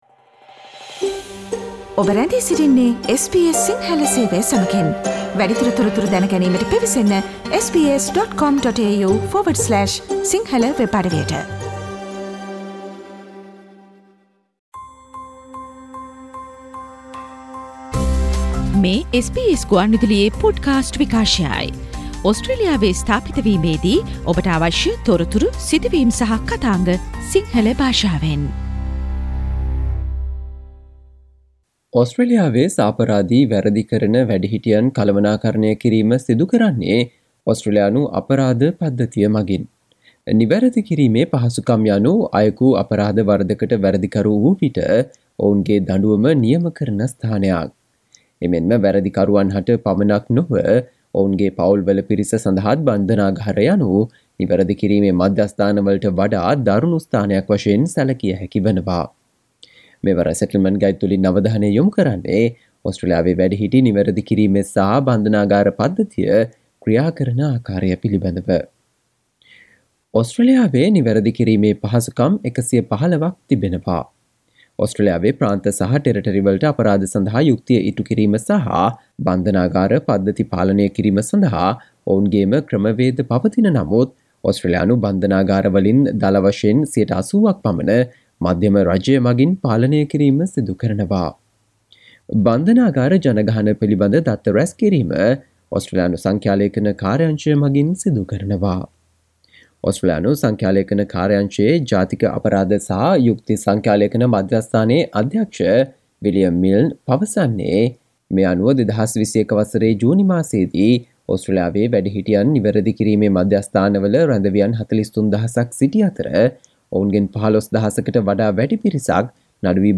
අද මෙම Settlement Guide ගුවන්විදුලි විශේෂාංගයෙන් අපි ඔබ වෙත ගෙන ආ තොරතුරු, කියවා දැන ගැනීමට හැකි වන පරිදි වෙබ් ලිපියක් ආකාරයටත් අපගේ වෙබ් අඩවියේ පලකොට තිබෙනවා.